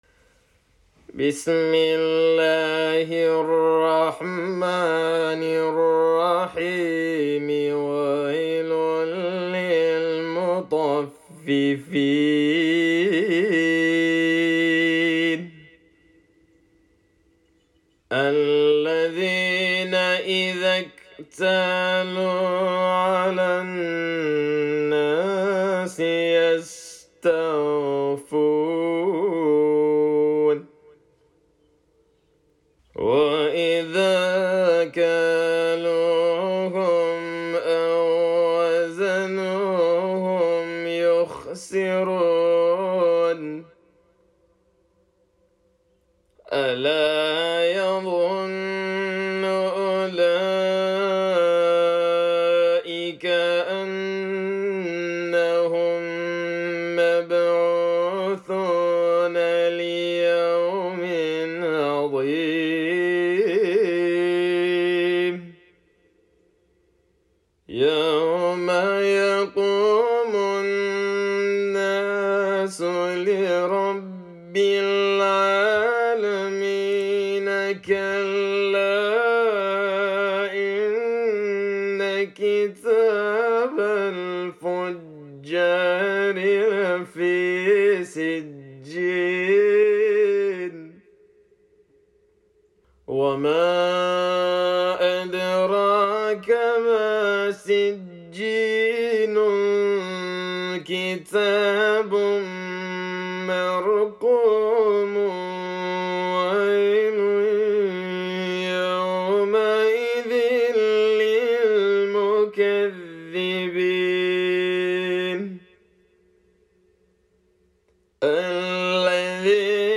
Chapter_83,_Al-Mutaffifin_(Mujawwad)_-_Recitation_of_the_Holy_Qur'an.mp3